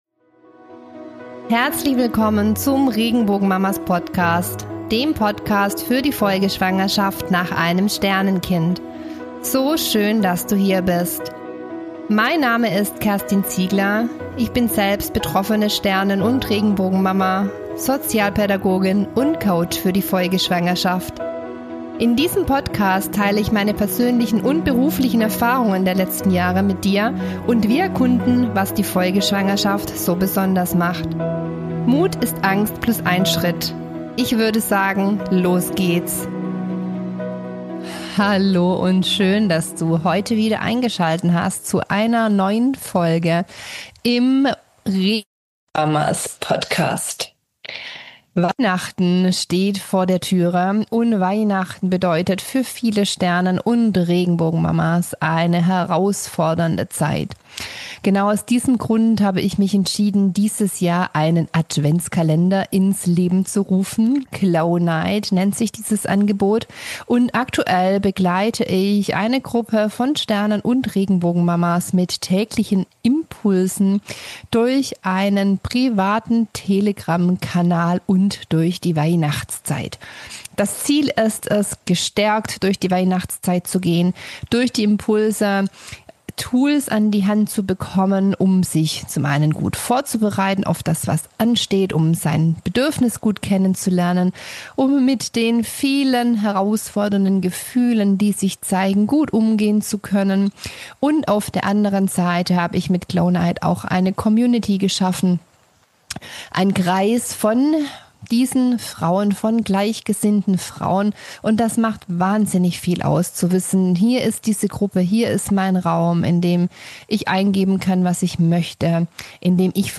Der Sound klingt daher an manchen Stellen etwas holprig, aber der Inhalt gleicht das doppelt wieder aus.